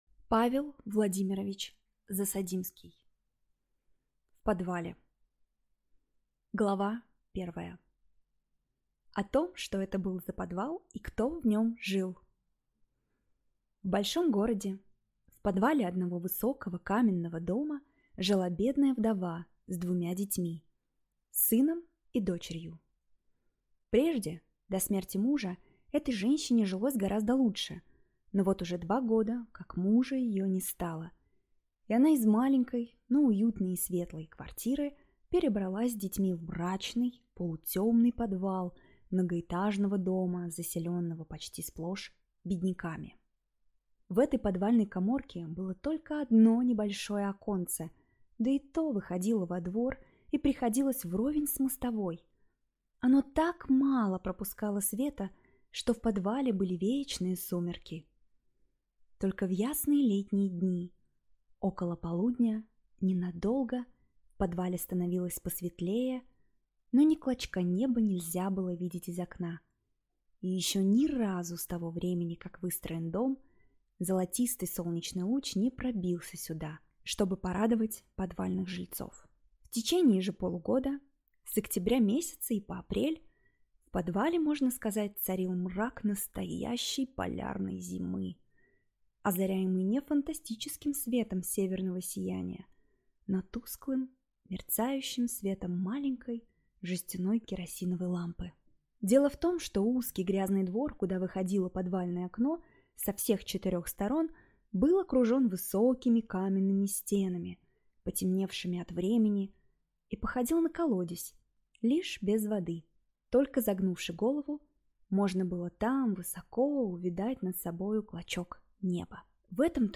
Аудиокнига В подвале | Библиотека аудиокниг
Прослушать и бесплатно скачать фрагмент аудиокниги